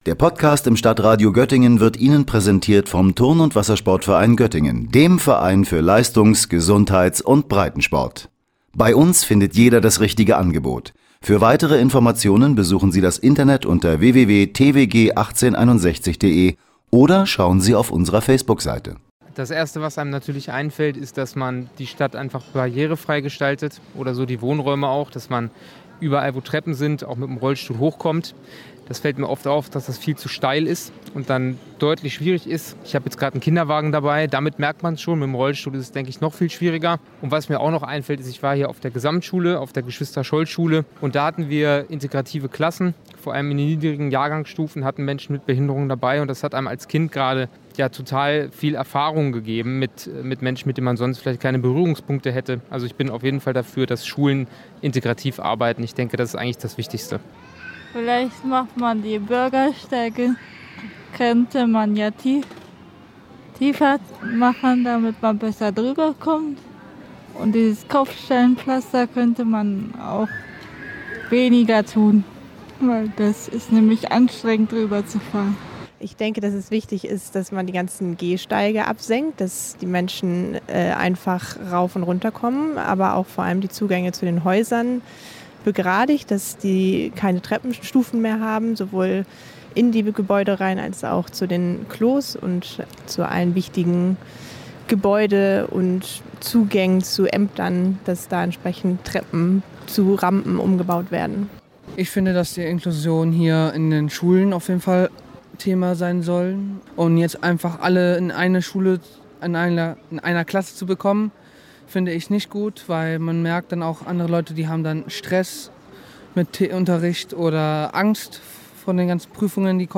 Wir haben uns in der Göttinger Innenstadt umgehört und sowohl Menschen mit als auch Menschen ohne körperliche Beeinträchtigung befragt.